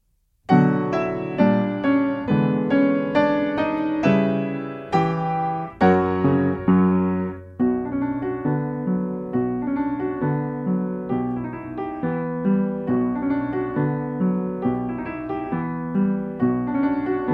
I część: 136 bmp
Nagranie dokonane na pianinie Yamaha P2, strój 440Hz
piano